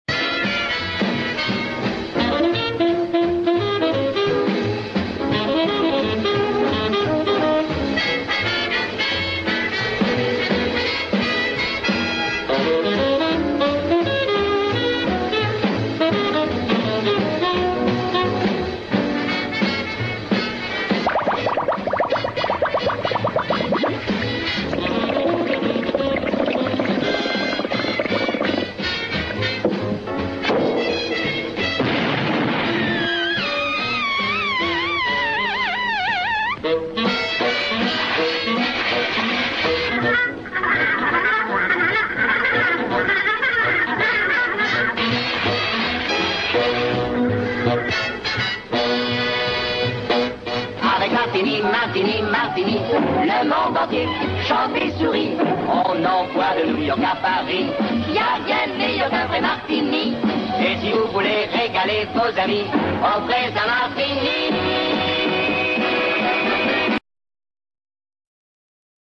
Le spot publicitaire complet